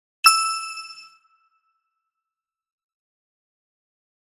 Звук дзынь в момент влюбленности